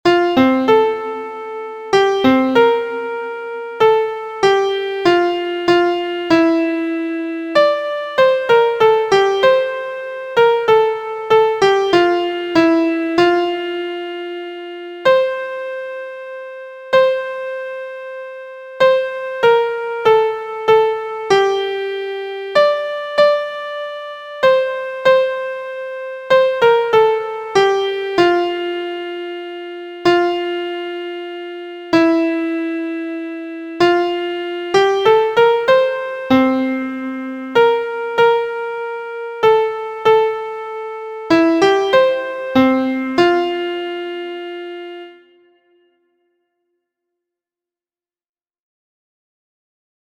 Three part round for voice and recorder: major sixth (M6), two minor sevenths (m7), dominate skip and dominate arpeggio.
• Key: F Major
• Time: 3/4
• Form: through composed (three phrases)
• Musical Elements: notes: dotted half, half, dotted quarter, quarter, eighth; syncopation, multiple vocal slurs, three part round in eight measure phrases, dominate arpeggio, dominate octave skip, two intervals of a minor seventh, singing in three parts
• Key Words: sacred, language: Latin; “Give us Peace,” Latin hymn, canon hymn, Italian Renaissance